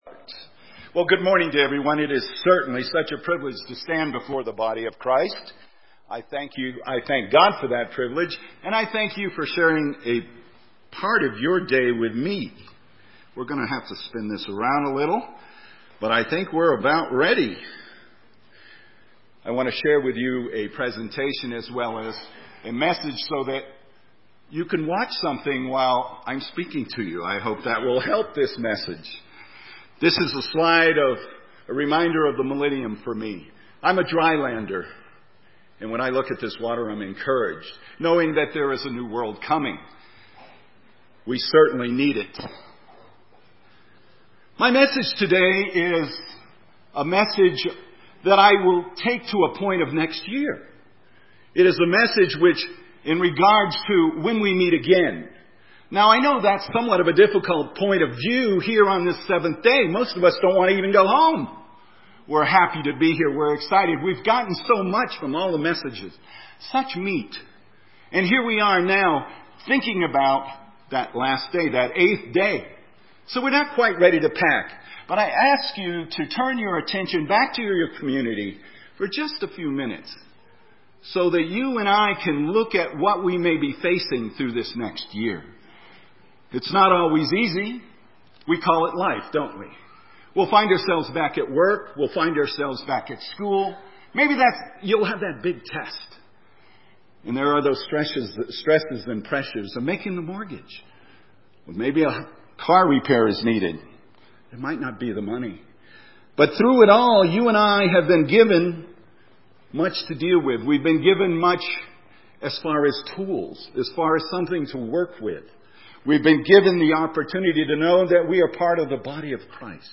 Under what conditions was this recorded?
This sermon was given at the Steamboat Springs, Colorado 2013 Feast site.